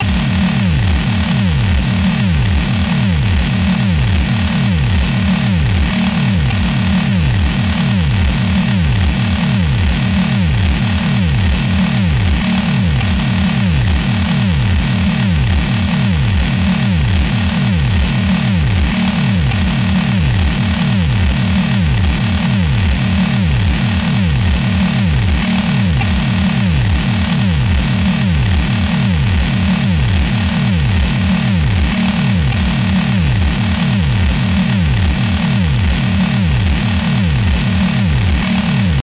ダンス、メタル、金属。